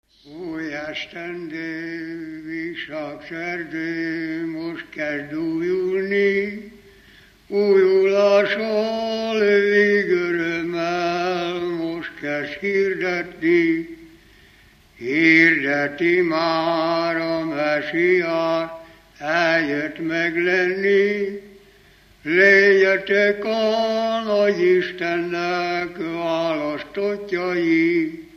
Alföld - Bihar vm. - Zsáka
ének
Műfaj: Újévi köszöntő
Stílus: 7. Régies kisambitusú dallamok